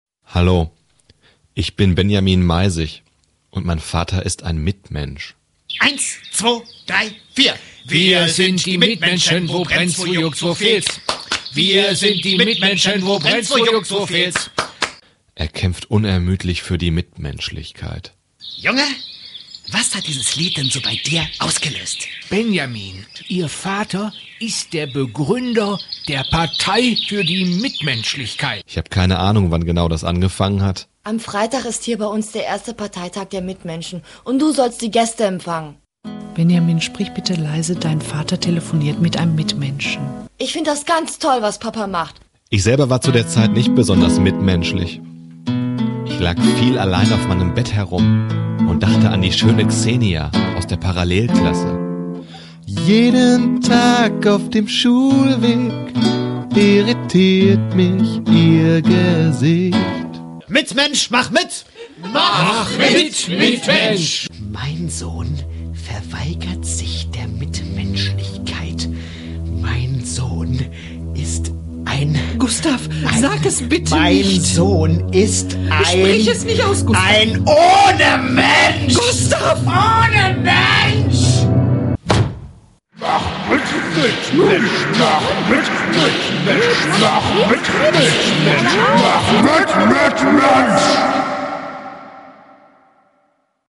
Ein Hörspiel, das als Familien(tragi-)komödie beginnt und als skurriler Polit-Thriller endet!
O Trailer